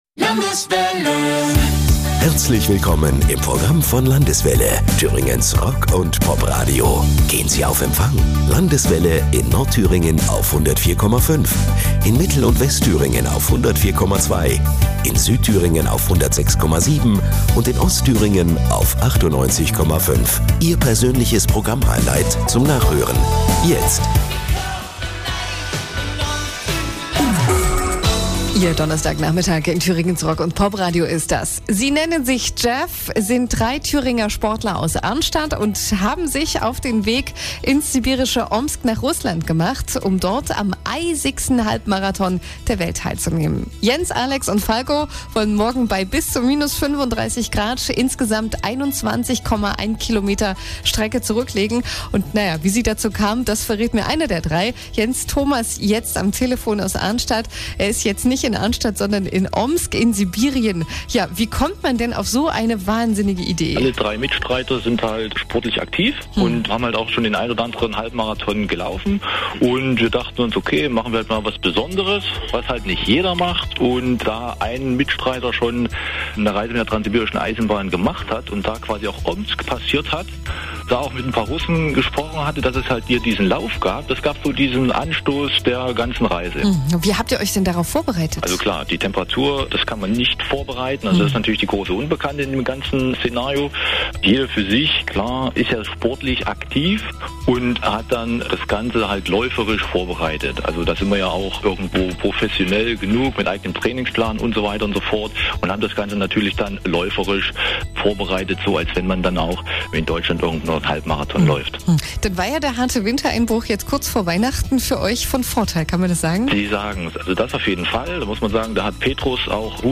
LIVE-Mitschnitt Landeswelle
Nach kurzem Telefoninterview mit Landeswelle Thüringen werden heute 13.20 Uhr deutscher Zeit ein paar Live-Eindrücke zu hören sein.
radio_landeswelle_JAF.mp3